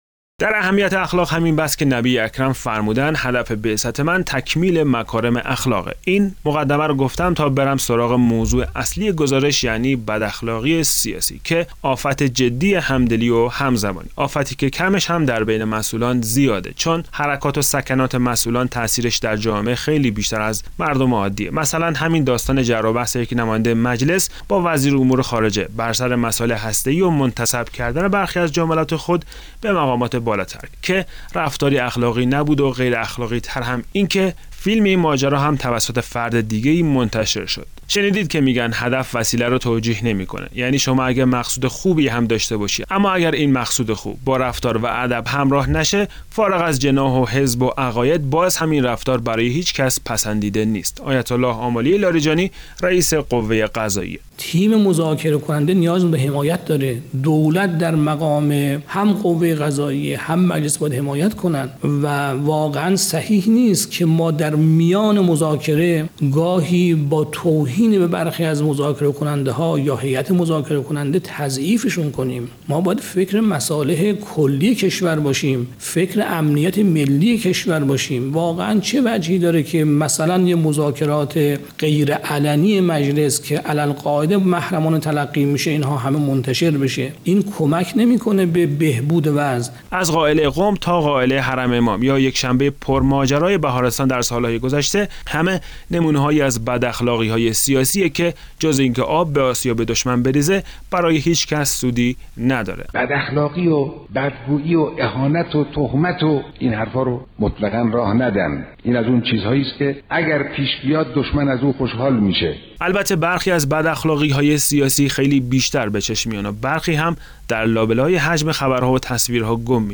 صوت/ قطع سخنرانی آیت الله جنتی در همایش فرمانداران - تسنیم
خبرگزاری تسنیم: صوت قطع سخنرانی آیت الله جنتی در همایش فرمانداران منتشر می‌شود.